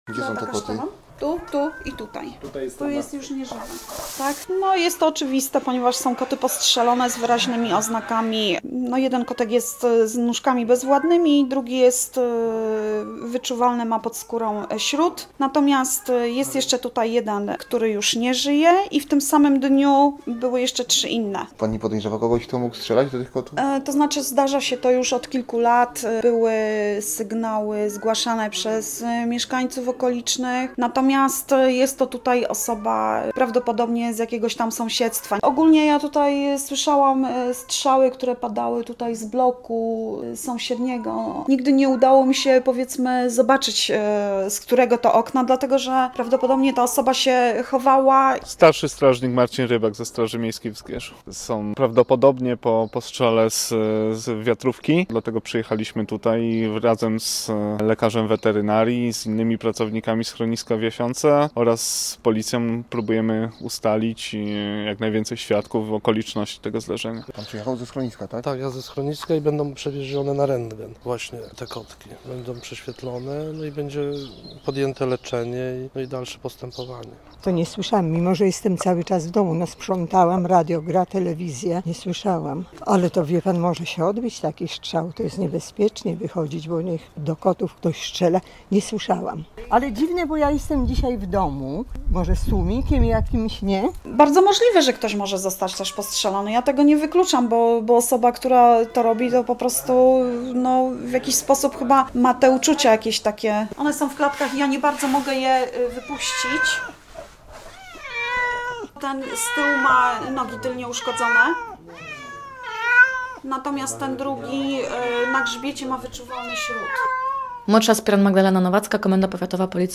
Posłuchaj relacji i dowiedz się więcej: Nazwa Plik Autor Kto strzela do kotów? audio (m4a) audio (oga) ZDJĘCIA, NAGRANIA WIDEO, WIĘCEJ INFORMACJI ZE ZGIERZA ZNAJDZIESZ W DZIALE “WIADOMOŚCI ZE ZGIERZA”.